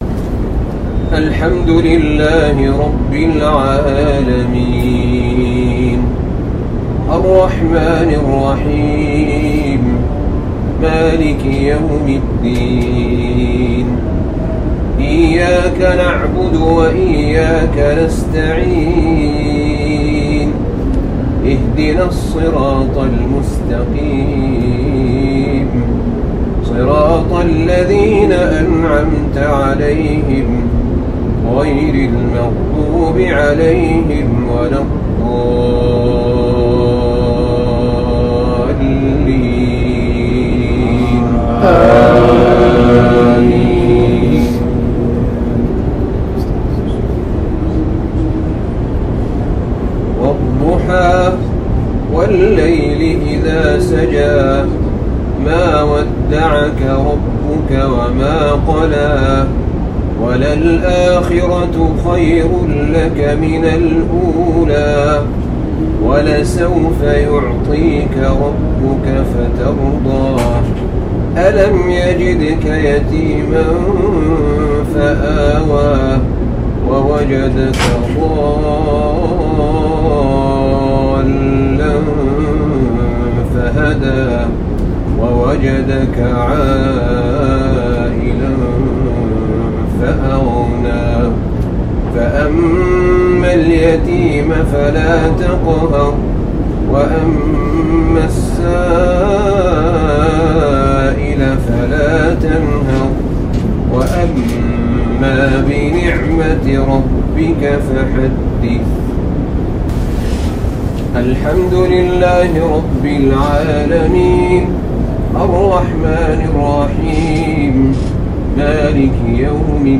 صلاتي المغرب والعشاء للشيخ أحمد بن طالب من مشعر منى يوم الأحد 11 ذو الحجة 1443هـ > تلاوات أئمة الحرمين من المشاعر المقدسة > المزيد - تلاوات الحرمين